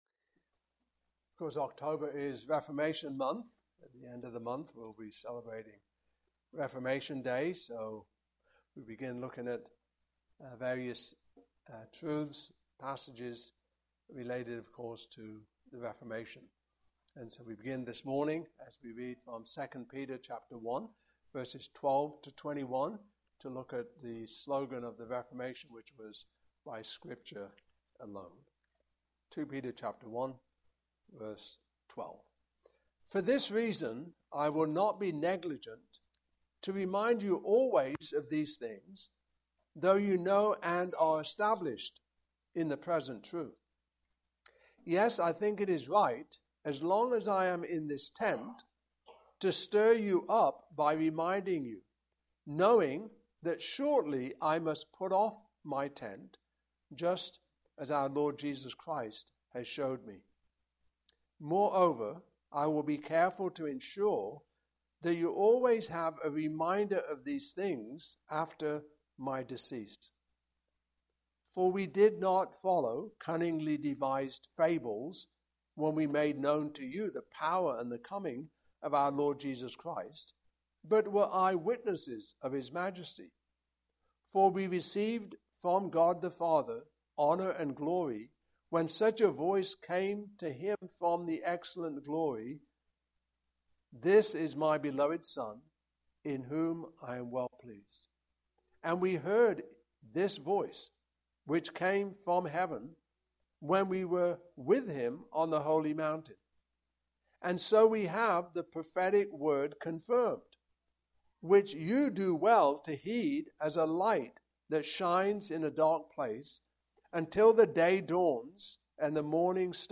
Passage: 2 Peter 1:12-21 Service Type: Morning Service